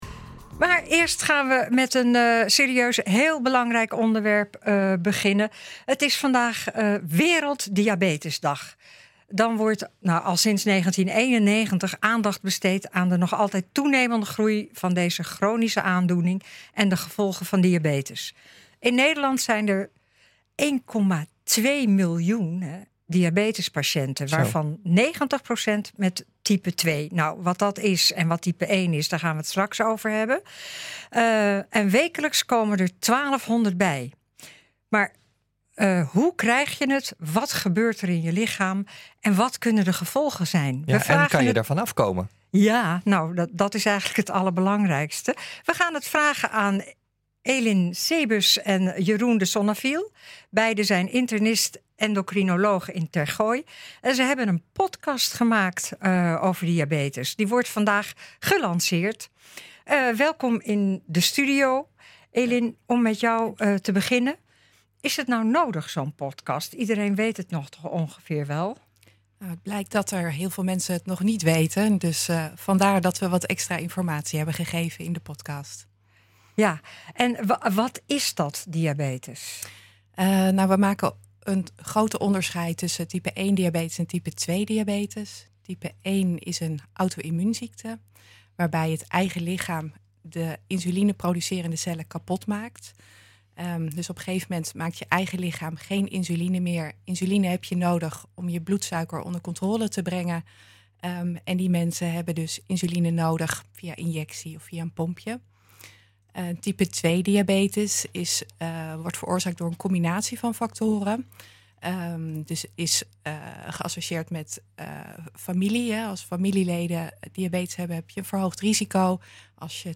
En zij zijn bij ons in de studio om hierover te vertellen.